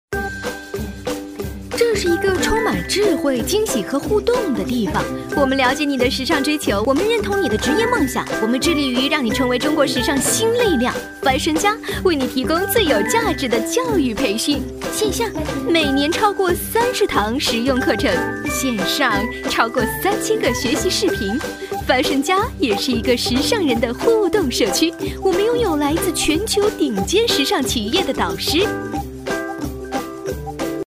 科技感配音【四海配音】
女141-飞碟说MG【智慧云商-科技-生活化】
女141-飞碟说MG【智慧云商-科技-生活化】.mp3